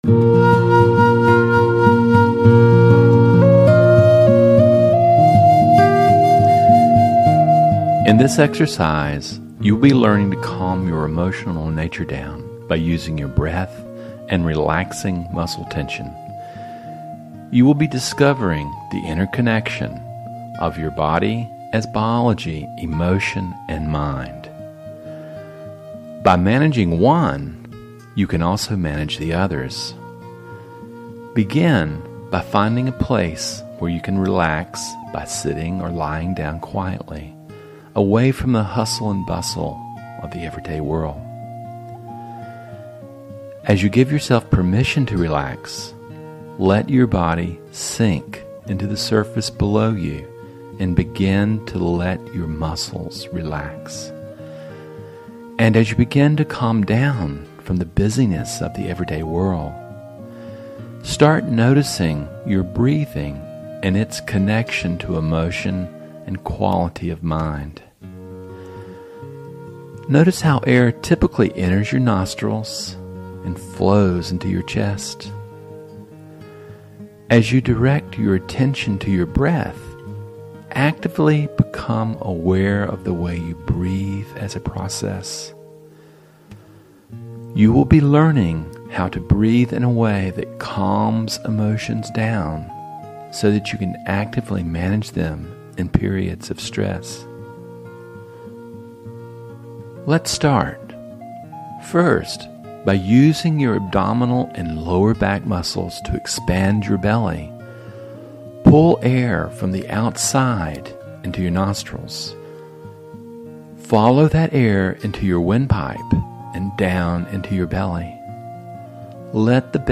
Breathing as Emotional Regulation - Guided Meditation.